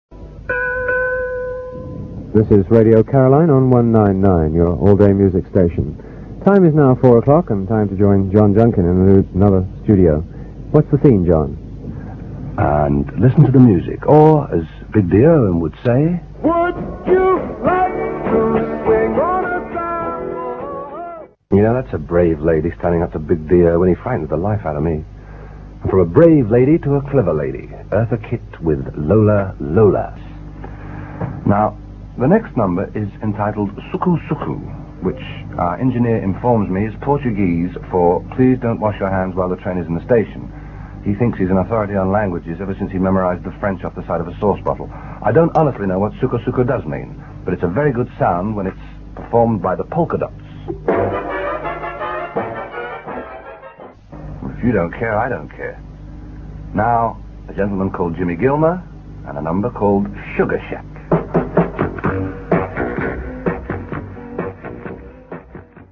click to hear audio Simon Dee introduces a pre-recorded show hosted by John Junkin, Radio Caroline 12th April 1964 (duration 1 minute 5 seconds)